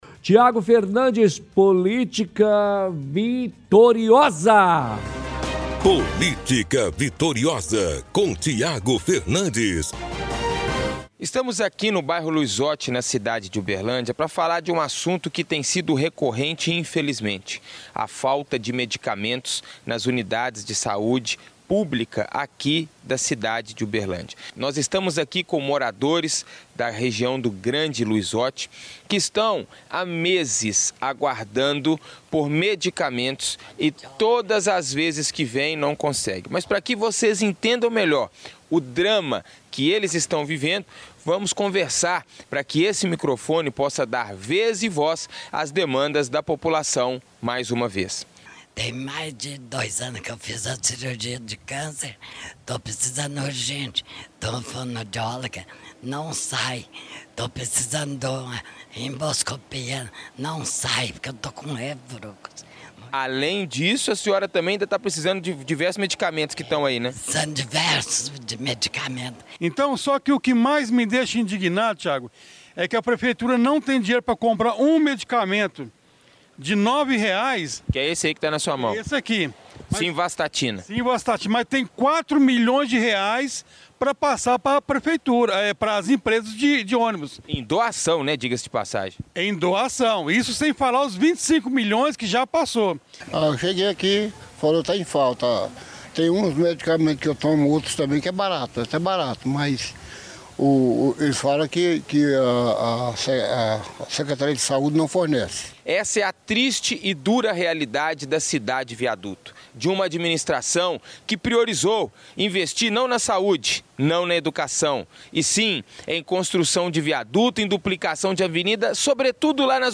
– Entrevista com cidadão que reclama da falta de medicamentos.